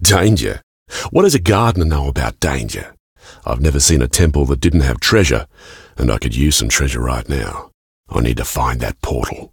B_self_talk.ogg